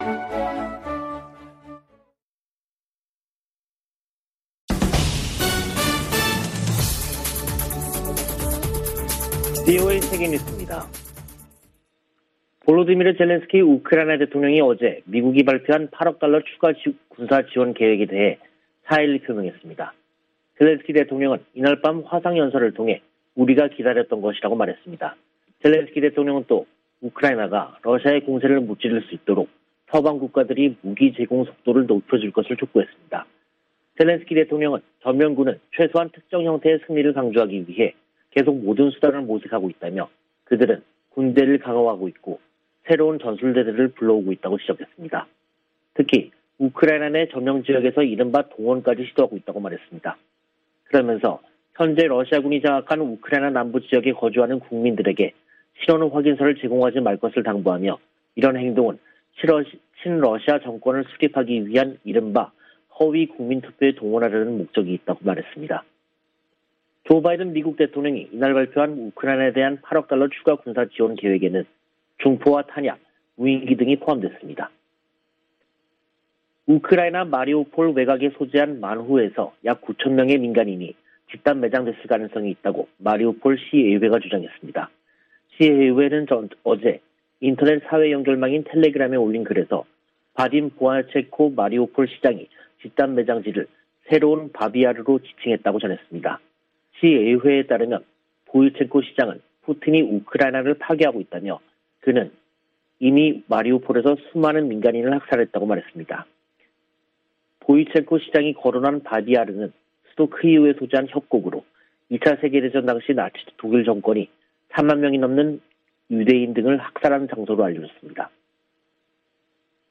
VOA 한국어 간판 뉴스 프로그램 '뉴스 투데이', 2022년 4월 22일 2부 방송입니다. 유럽연합(EU)이 북한의 잇단 미사일 발사에 대응해 북한 개인 8명과 기관 4곳을 독자제재 명단에 추가했습니다. 미 국무부는 북한의 도발에 계속 책임을 물리겠다고 경고하고, 북한이 대화 제안에 호응하지 않고 있다고 지적했습니다. 문재인 한국 대통령이 김정은 북한 국무위원장과 남북 정상선언의 의미를 긍정적으로 평가한 친서를 주고 받았습니다.